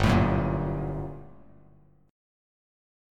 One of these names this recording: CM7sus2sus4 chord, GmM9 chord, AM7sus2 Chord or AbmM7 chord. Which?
GmM9 chord